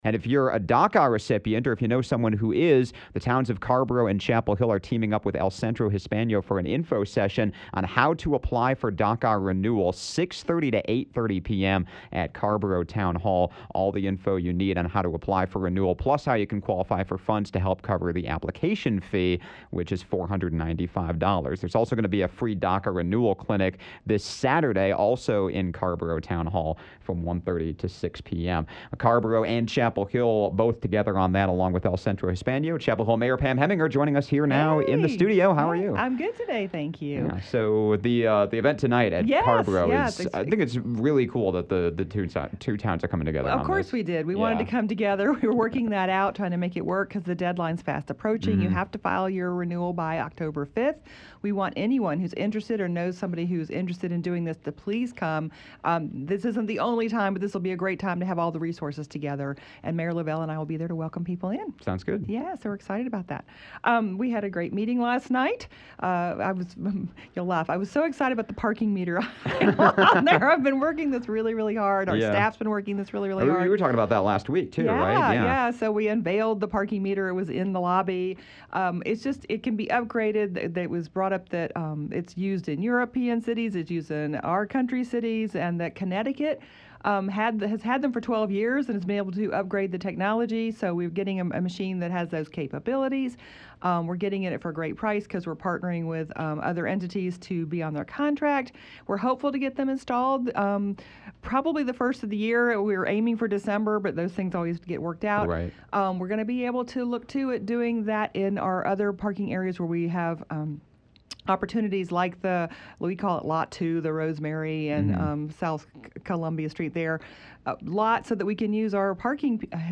Conversations with the Mayors